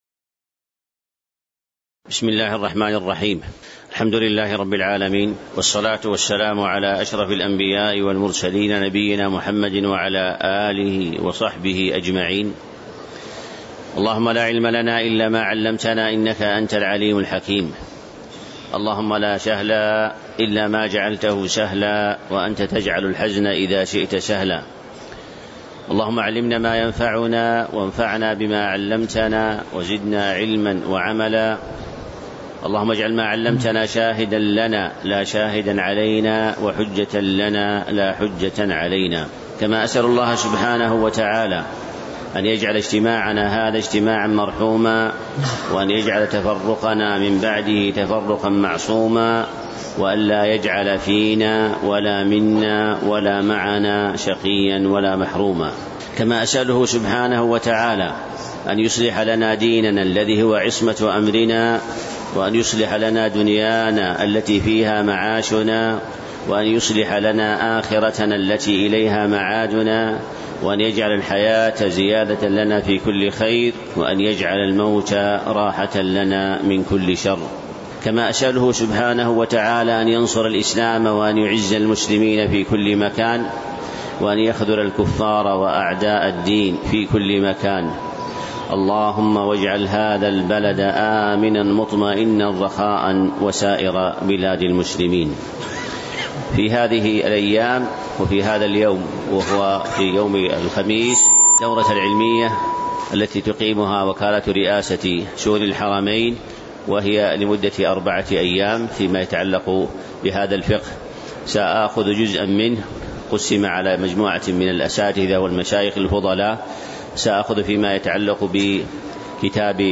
تاريخ النشر ٢٤ ذو القعدة ١٤٤٣ هـ المكان: المسجد النبوي الشيخ